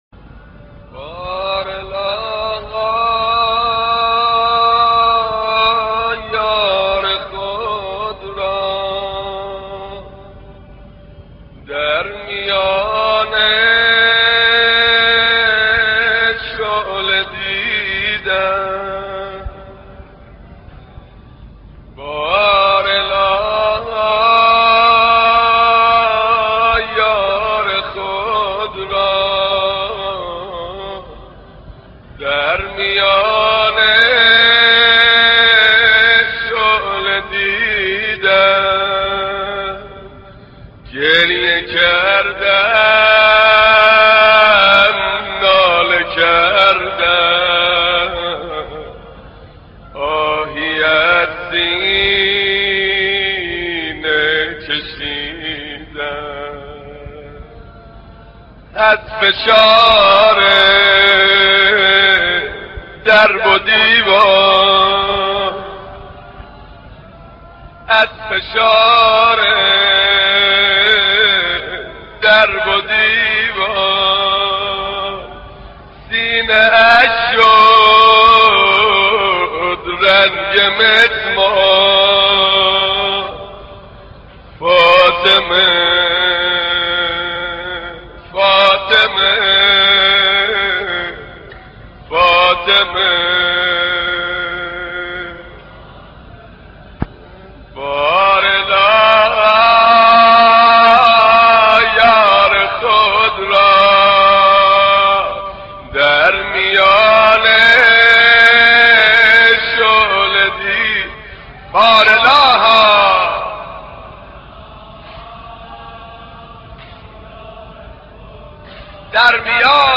دانلود مداحی بار الها یار خود را در میان شعله دیدم - دانلود ریمیکس و آهنگ جدید